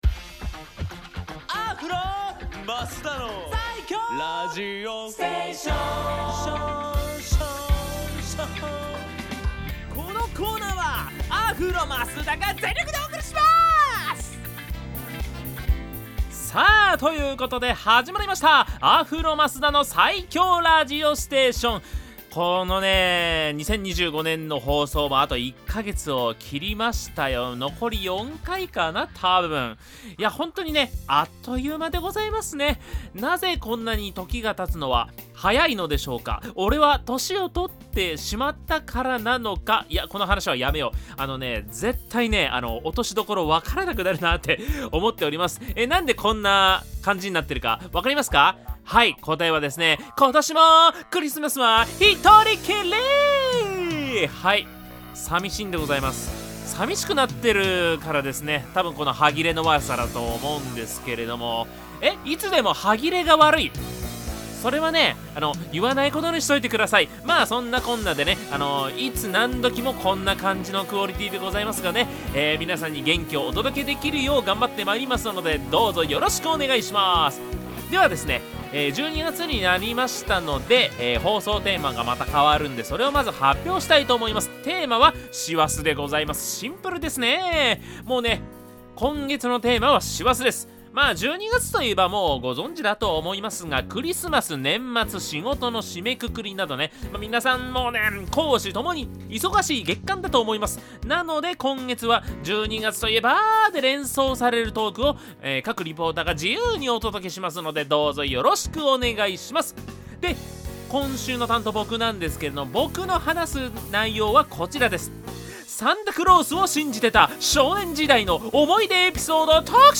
こちらのブログでは、FM83.1Mhzレディオ湘南にて放送されたラジオ番組「湘南MUSICTOWN Z」内の湘南ミュージックシーンを活性化させる新コーナー！